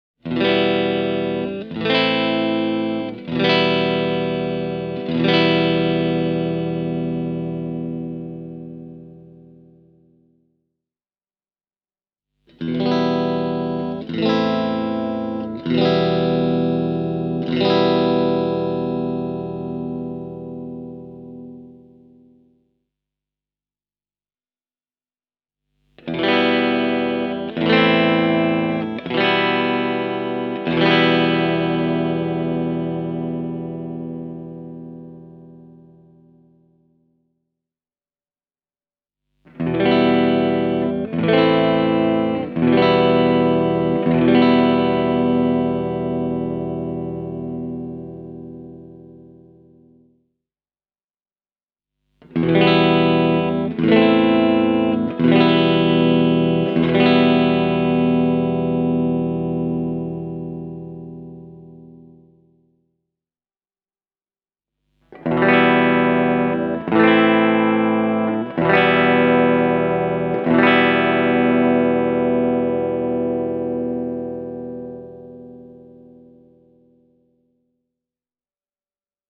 This tonal breadth is down to this models excellent pickups. Both the Sentient and the Pegasus have been designed for Metal, but their relatively moderate output lets the music breathe.
As you can easily hear in this clip, the MH-1000NT sounds great in clean settings, too (the split pickups come first):